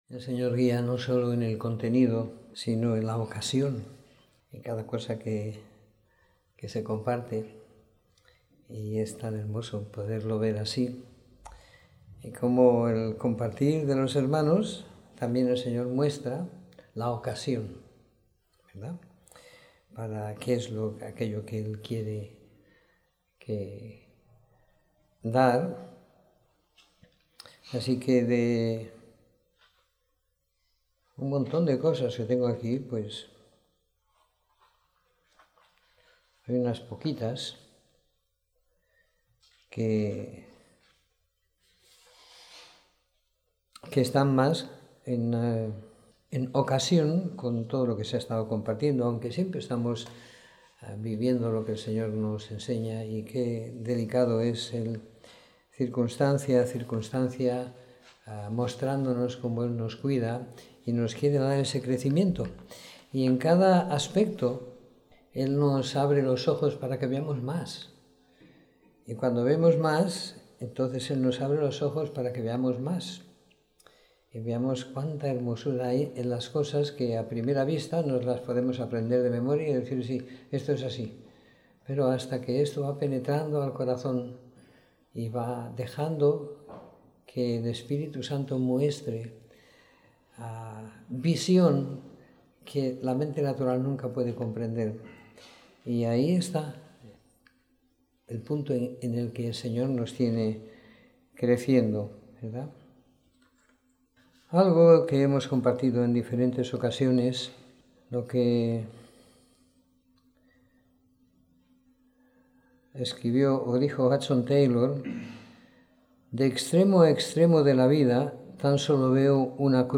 Domingo por la Tarde . 30 de Octubre de 2016